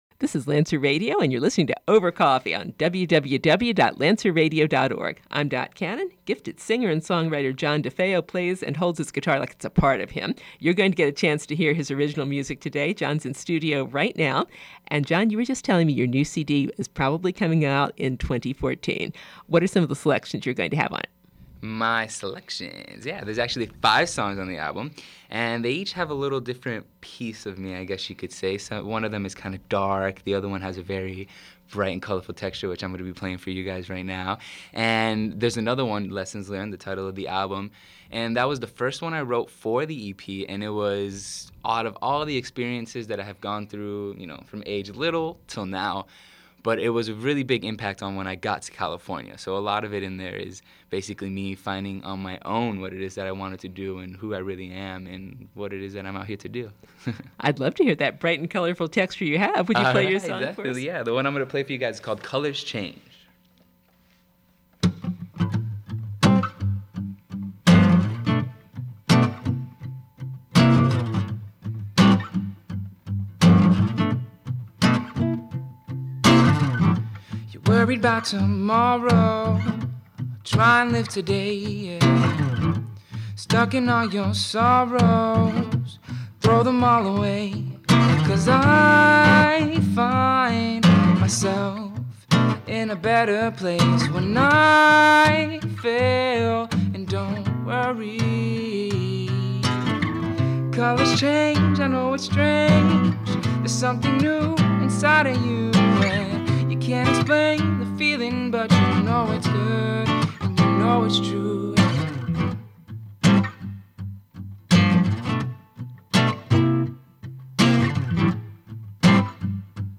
Interview, Part Two